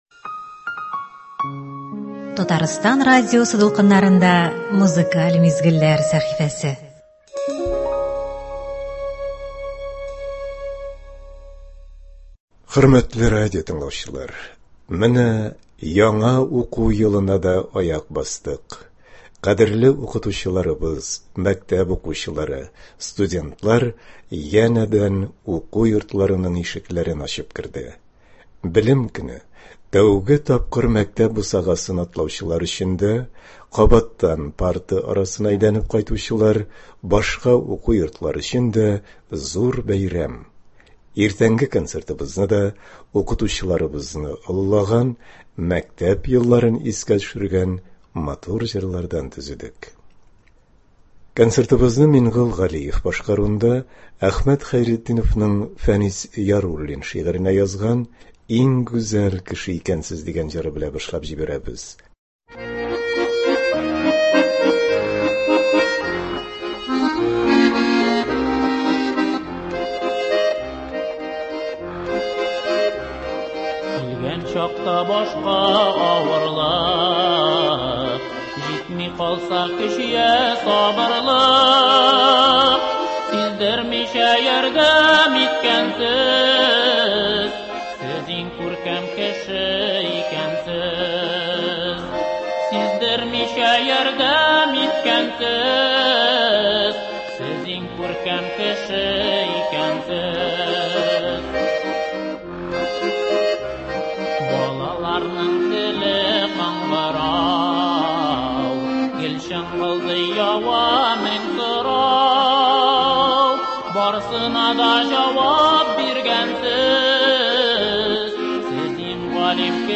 Бүгенге концертыбыз Белем көненә багышлана